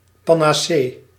Ääntäminen
IPA : /ˌpæn.əˈsiː.ə/